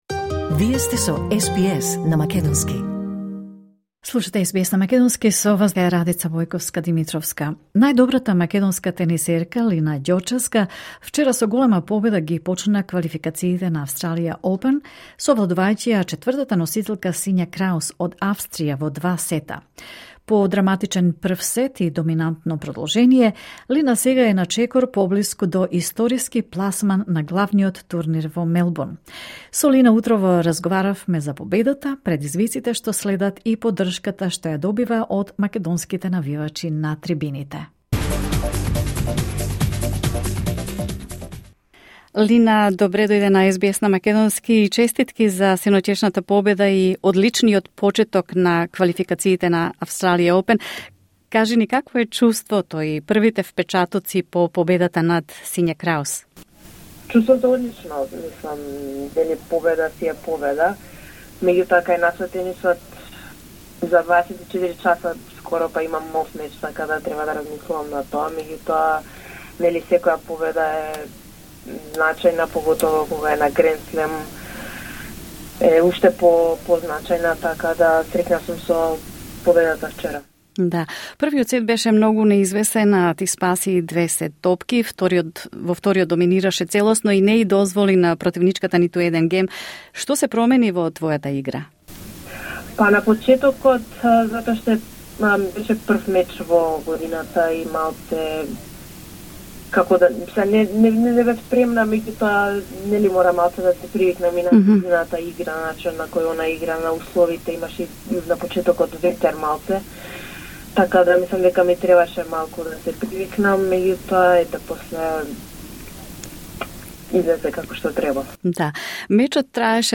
Со неа утрово разговараме за победата, предизвиците што следат и поддршката што ја добива од македонските навивачи.